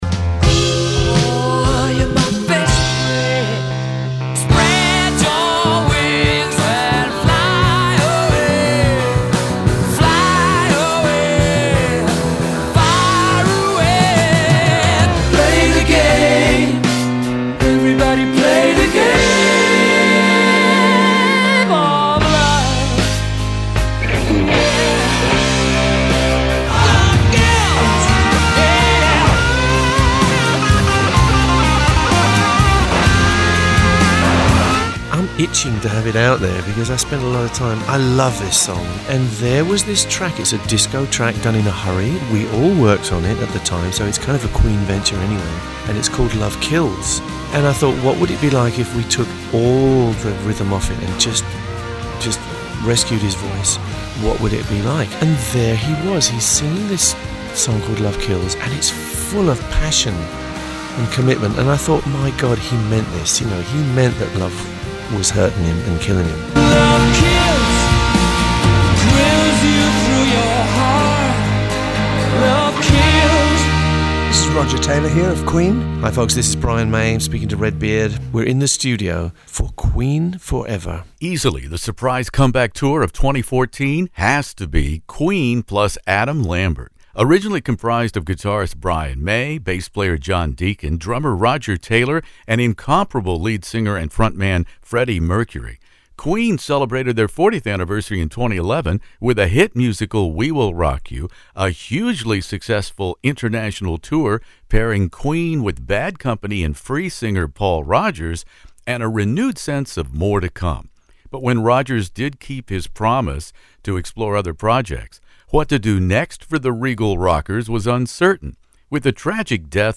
Hear the stories behind these remarkable discoveries from Roger Taylor and Brian May in this In the Studio classic rock interview.